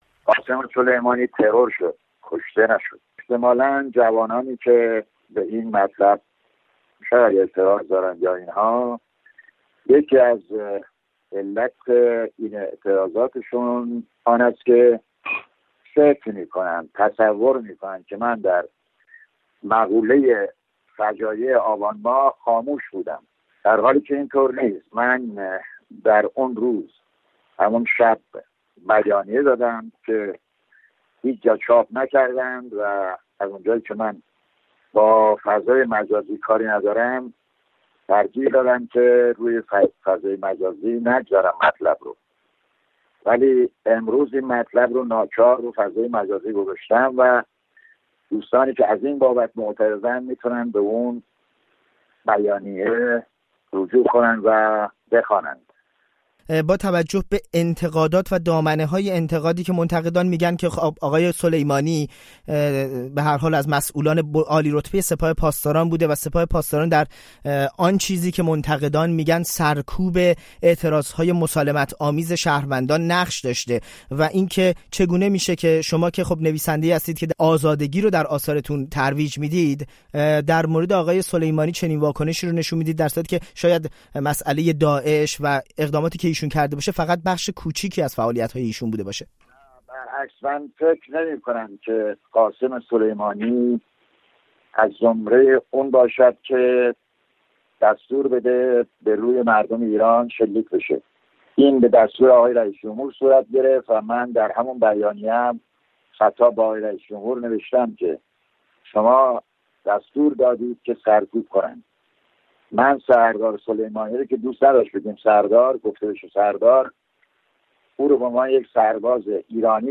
دفاع دولت آبادی از یادداشت خود در سوگ سلیمانی در مصاحبه با رادیو فردا